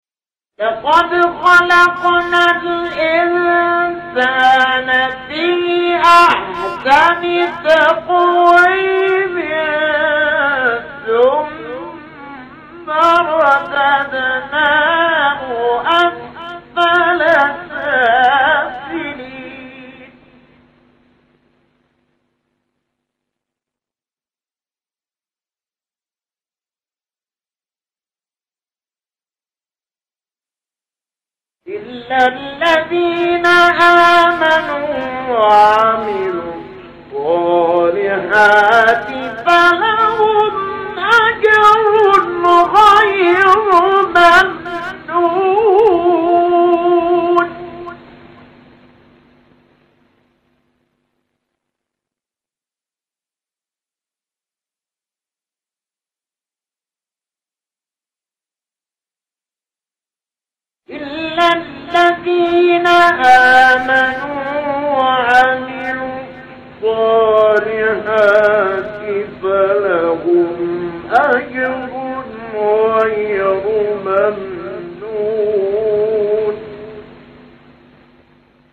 مقطع تلاوت 6-4 تین شیخ علی محمود | نغمات قرآن
مقام: بیات * رست * چهارگاه